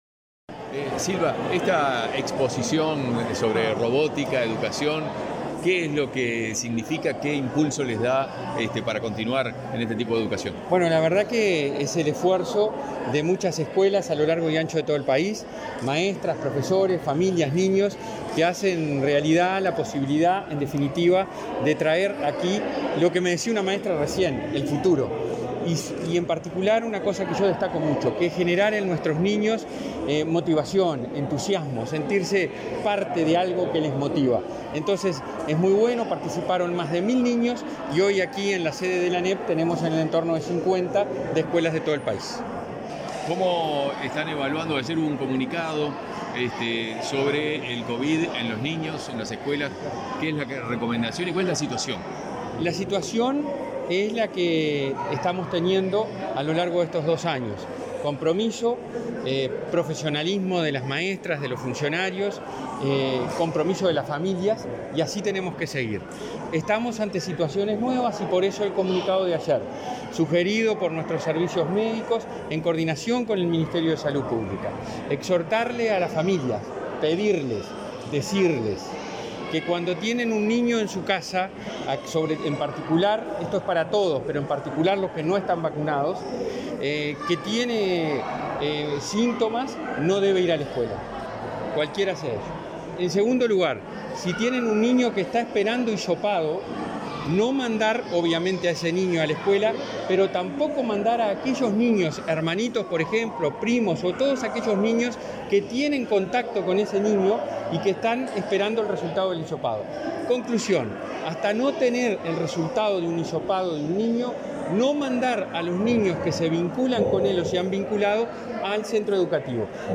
Declaraciones de prensa del presidente del Codicen, Robert Silva
Este 22 de octubre, en el marco del 7.° Día Mundial de TortugArte y el cierre de la Semana de la Robótica y la Programación, estudiantes de Montevideo y Colonia presentaron sus trabajos de robótica en la sede de la Administración Nacional de Educación Pública (ANEP). Tras el evento, el presidente del Codicen, Robert Silva, efectuó declaraciones a la prensa.